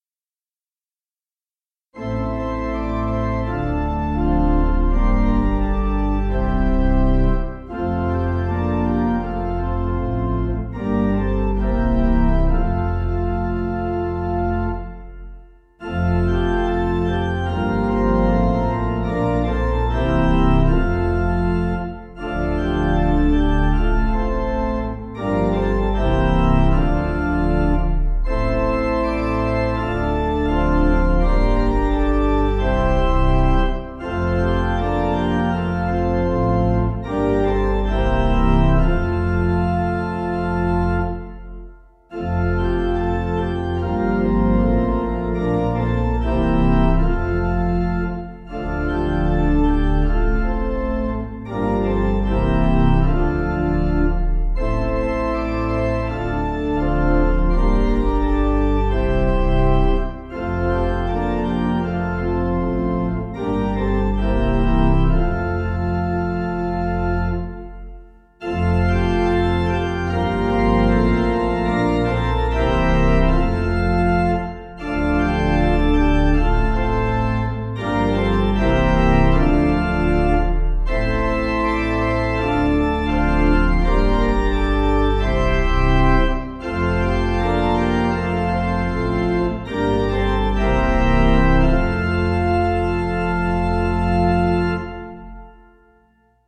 Hungarian Melody
Organ